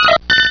sovereignx/sound/direct_sound_samples/cries/togepi.aif at master